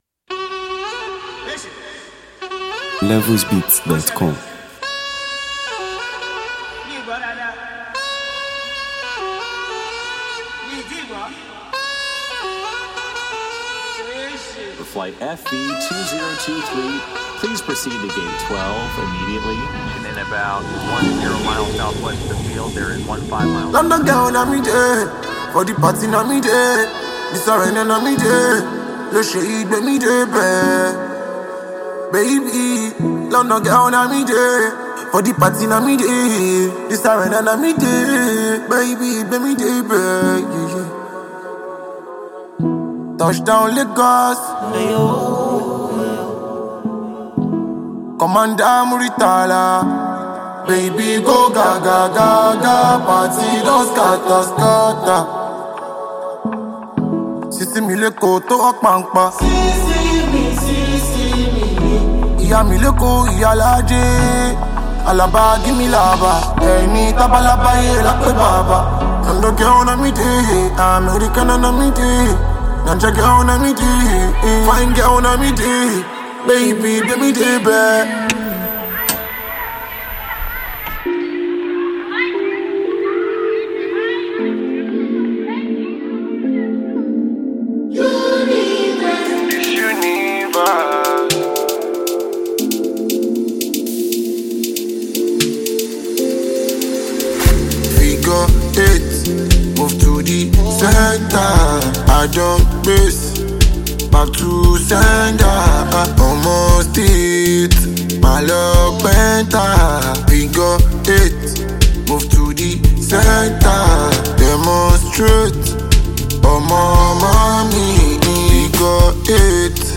A Soul-Stirring Anthem
With its compelling rhythm, soulful message,